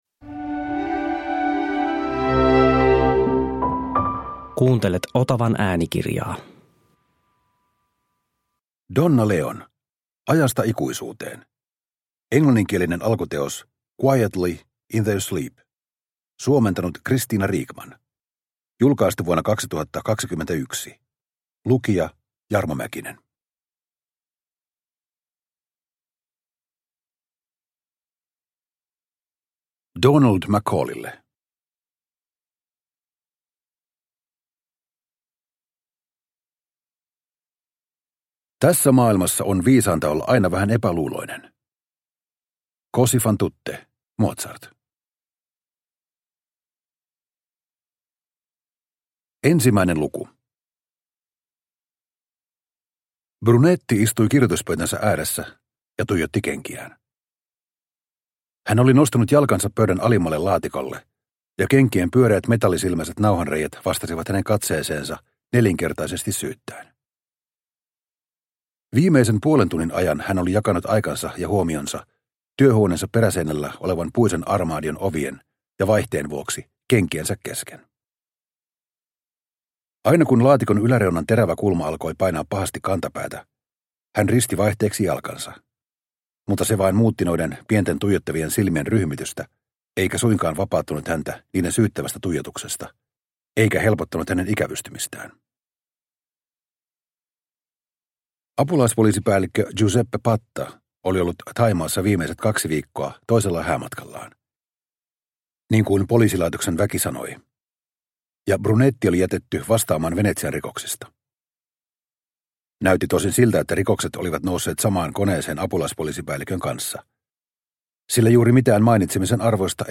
Ajasta ikuisuuteen – Ljudbok – Laddas ner